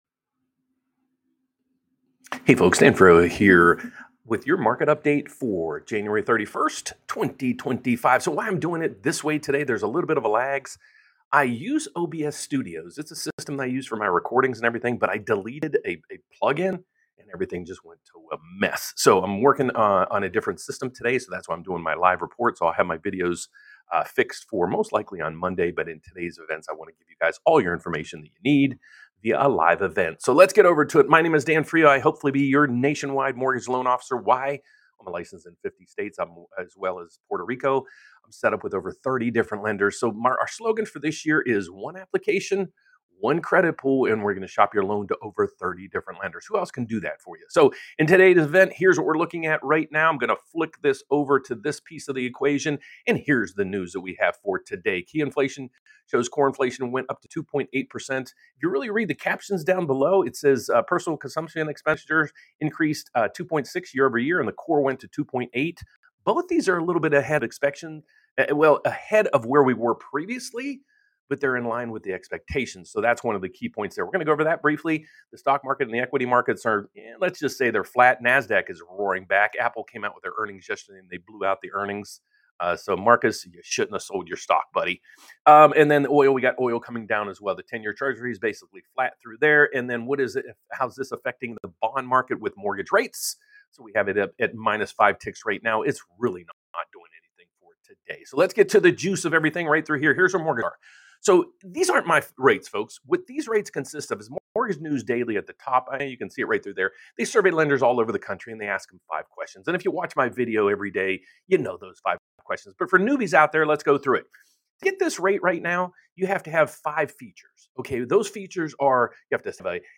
FED FREEZES RATES: Will Stocks Rally? Real Estate Boom? LIVE Analysis & Predictions!